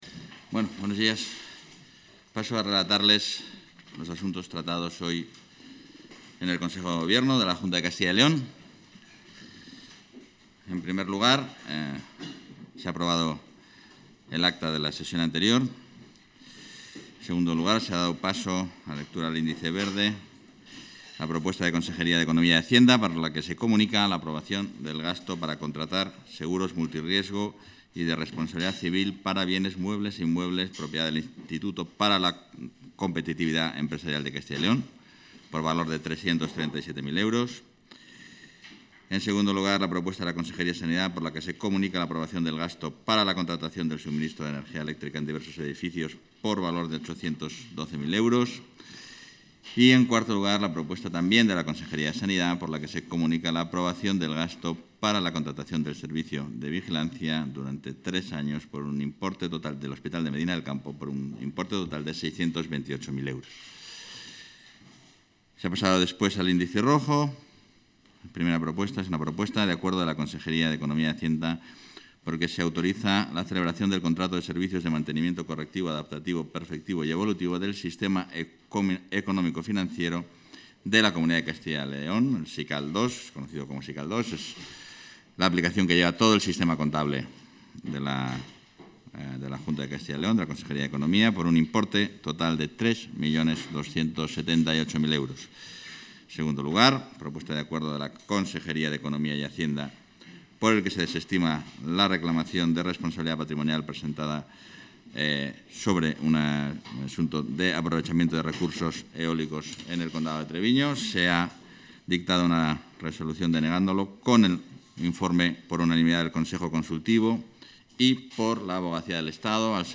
Rueda de prensa tras Consejo de Gobierno.